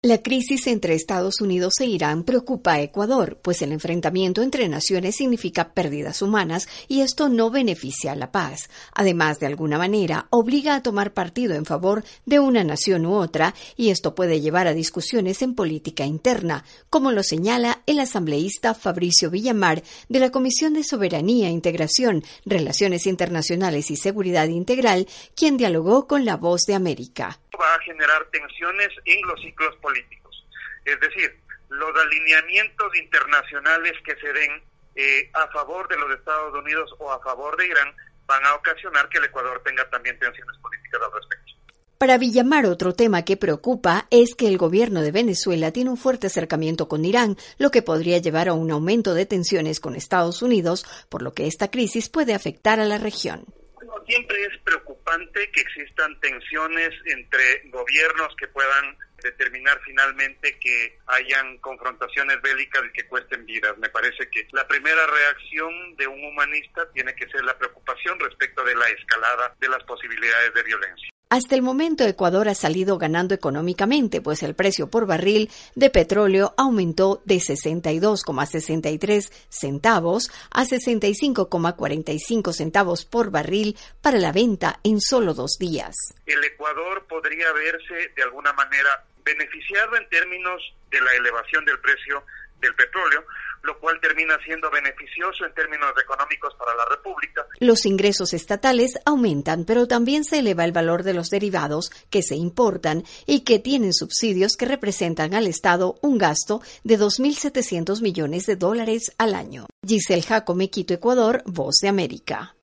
VOA: Informe de Ecuador